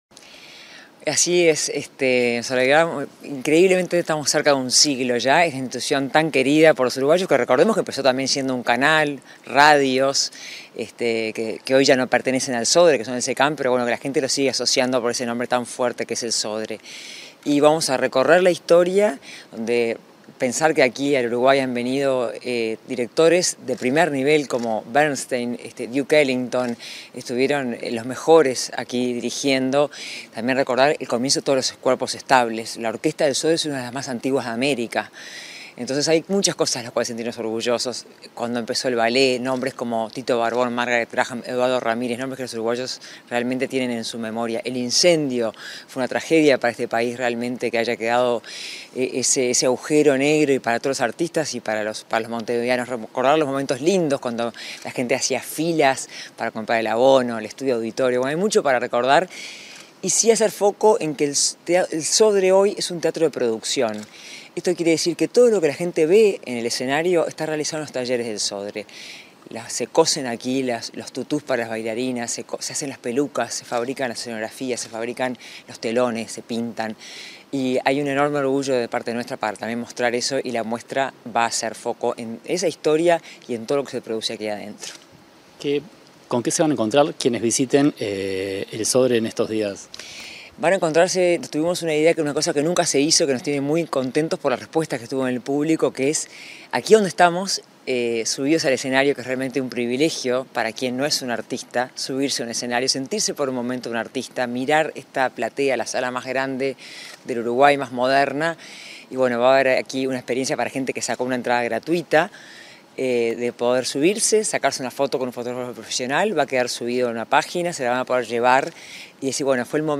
Entrevista a la presidenta del Sodre, Adela Dubra